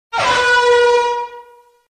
Lethal Company Air Horn
lethal-company-air-horn.mp3